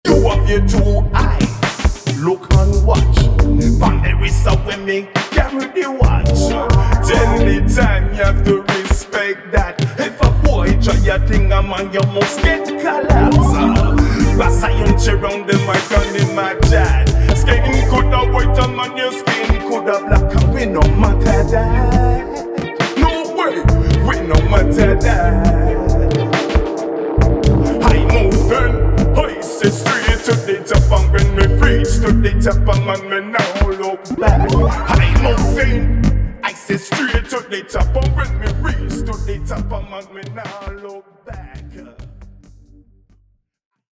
伝統的な要素と先鋭的な要素がブレンドされたレゲエ作品に仕上がっています。